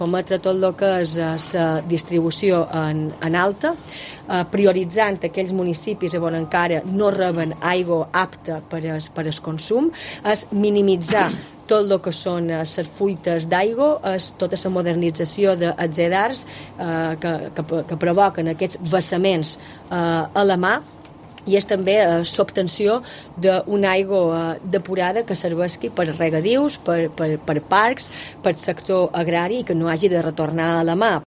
La Presidenta del Govern, Marga Prohens habla de los objetivos de esta inversión